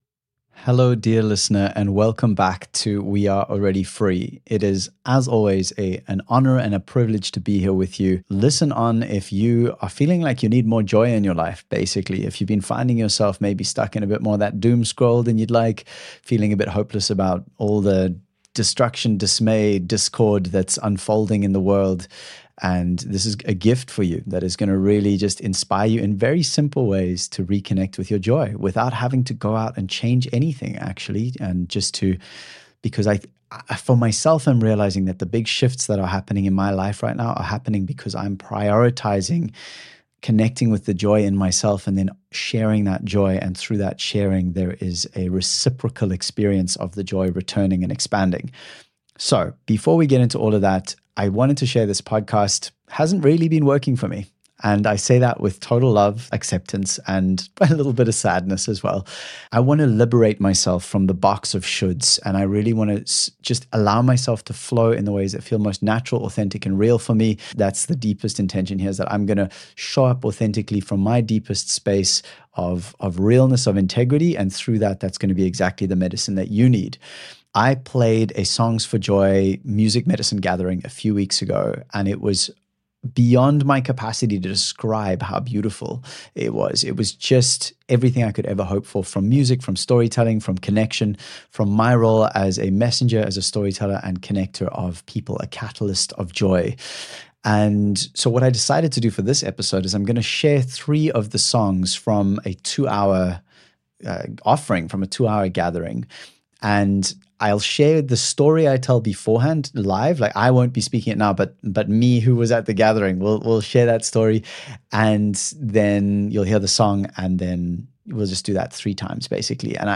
If heaviness is dragging you down, these live songs and raw stories are here to reignite your inner spark. You don’t need to fix anything…just gently surrender to stories of loss, rebirth, and connection.